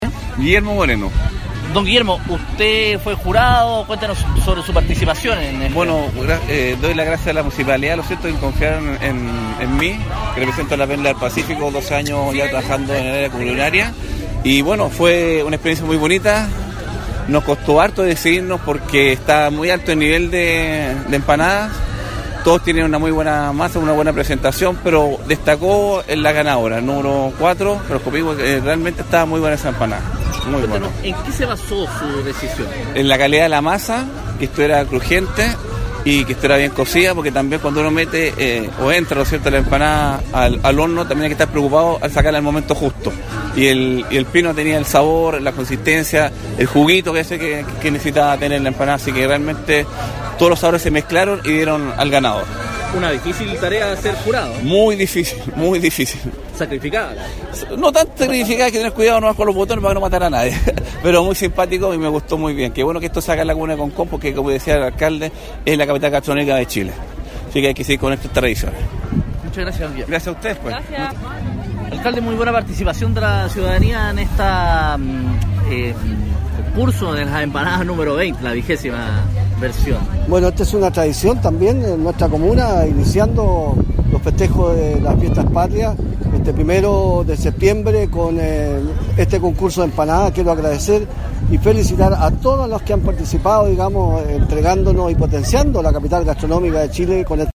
Para comenzar el mes de la patria como corresponde, en la Plaza Patricio Lynch de Concón se realizó el “XX Concurso de Empanadas 2018”, donde12 organizaciones comunitarias compitieron para ser la mejor preparación de esta tradicional comida chilena.
JURADO-X-EMPANADAS-3-.mp3